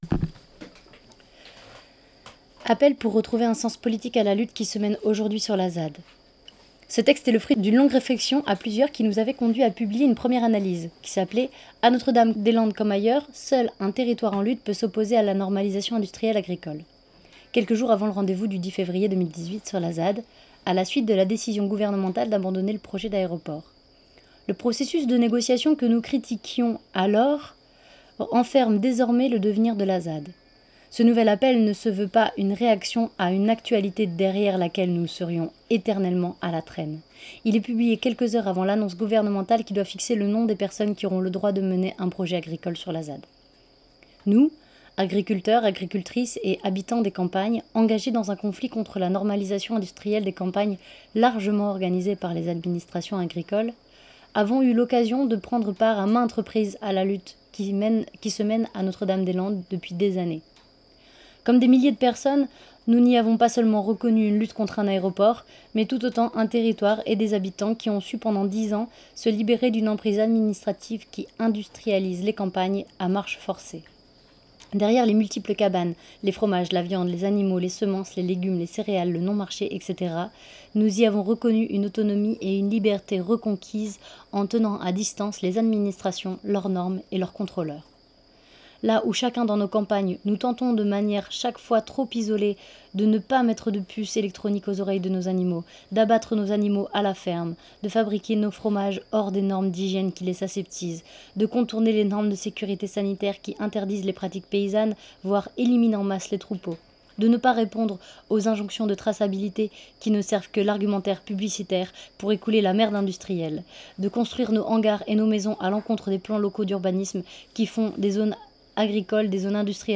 Nous avons été touché·e·s par ce texte, que nous avons donc lu et enregistré, afin de pouvoir le diffuser.